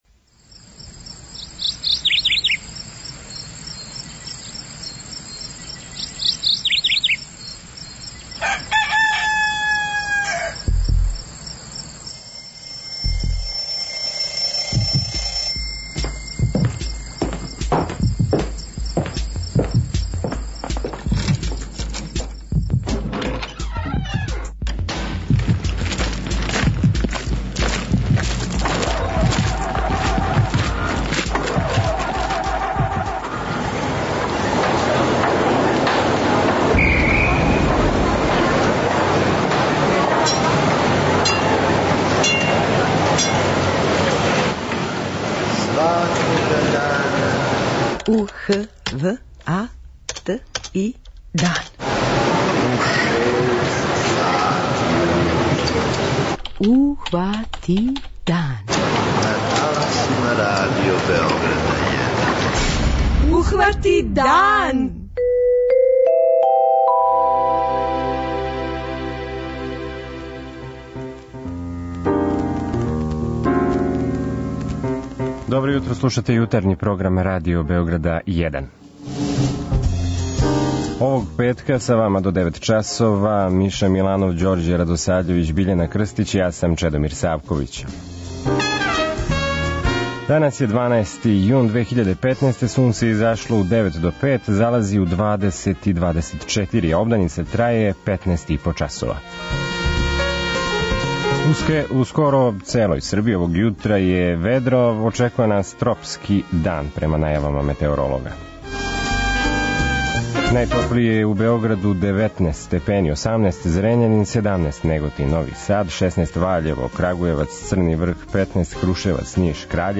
Петком је и наш квиз знања, а најуспешнијег слушаоца очекује вредна награда.